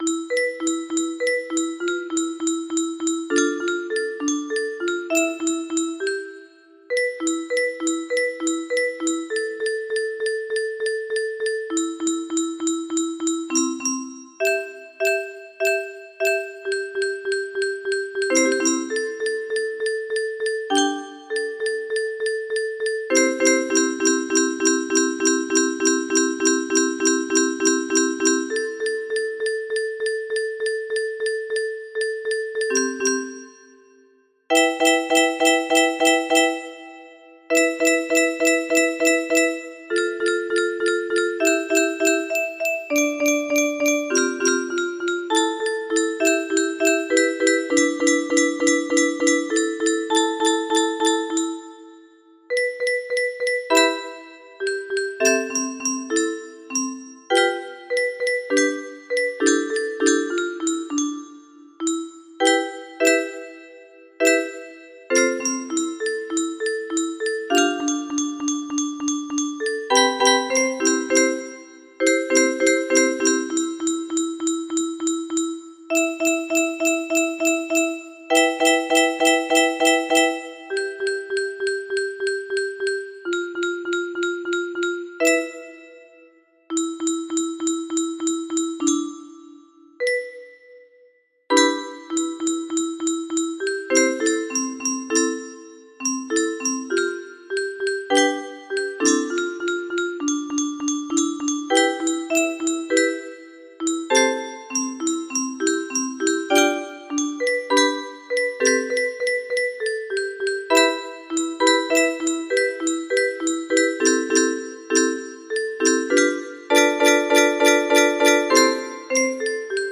painstakingly transcribed into 15 notes, listen creatively